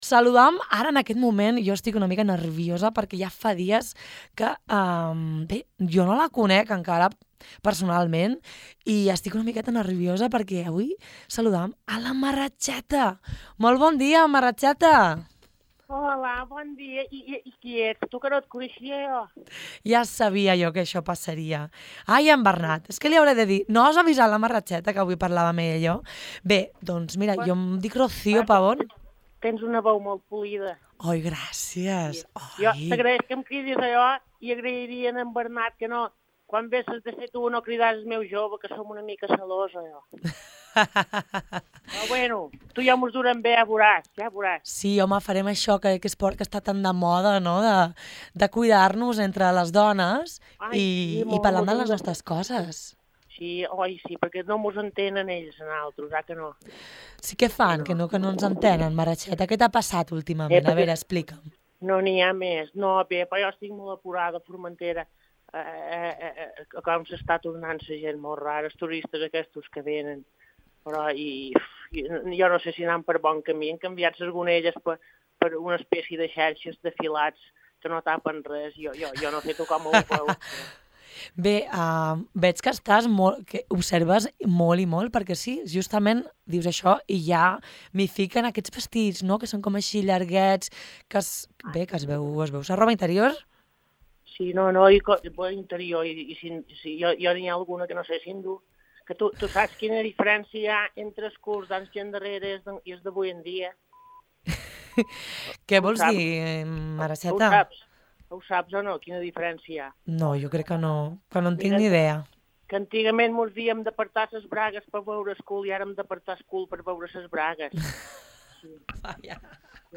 Sàtira i rialles sobre els fets estiuencs al teu abast, clica aquest enllaç per sentir l’entrevista al complet.